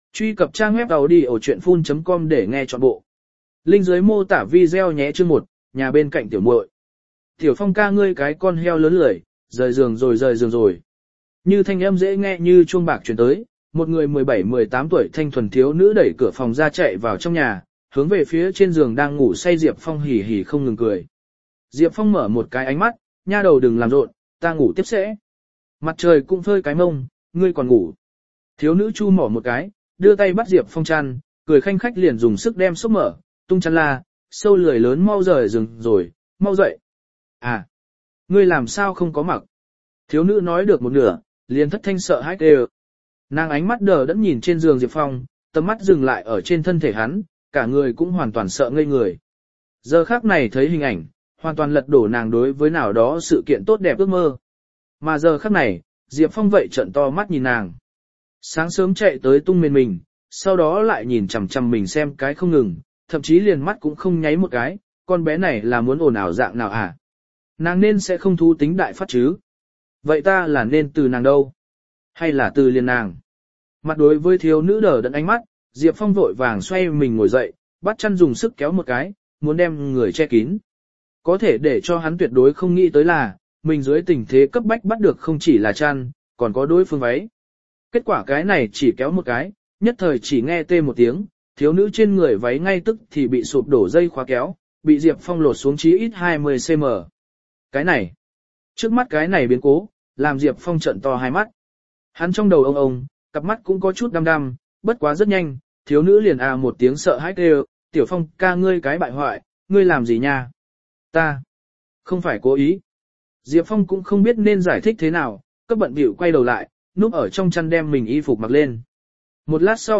Đô Thị Vô Thượng Y Thần Audio - Nghe đọc Truyện Audio Online Hay Trên TH AUDIO TRUYỆN FULL